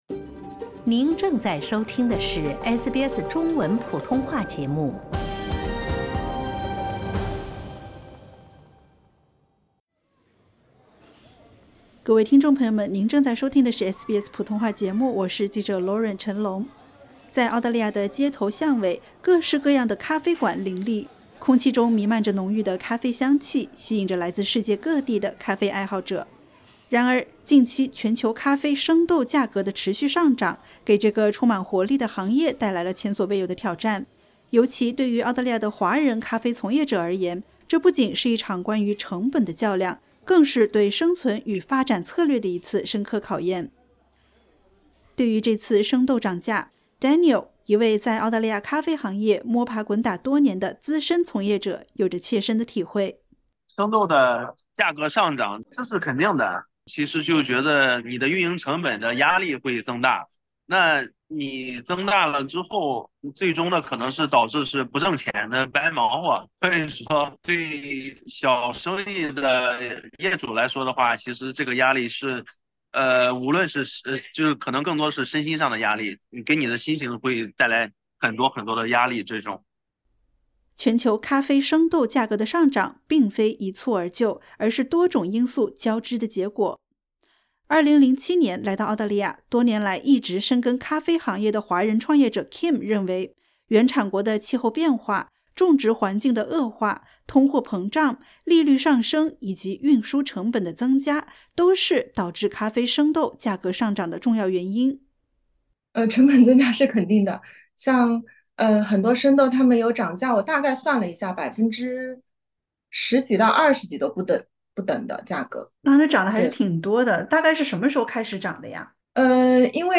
近期全球咖啡生豆价格持续上涨，咖啡会不会变得“喝不起”了？澳大利亚华人咖啡从业者们面对这一挑战，如何站稳脚跟，甚至迎来扩张与成长的新机遇？点击 ▶ 收听完整采访。